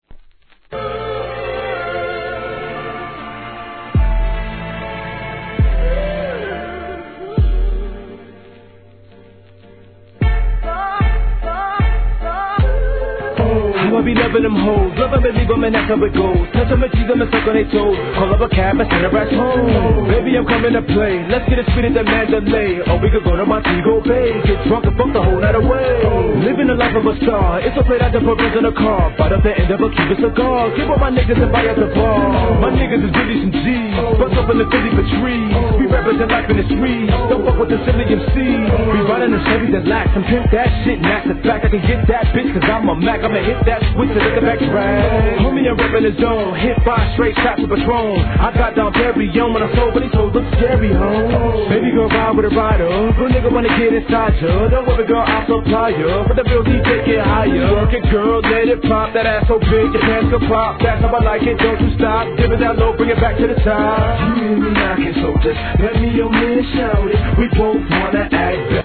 G-RAP/WEST COAST/SOUTH
スリリングなクラップ・バウンス!!